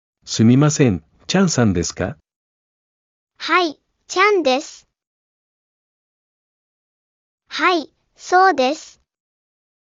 Nghe và nhắc lại đoạn hội thoại giữa thầy Yamada và Trang.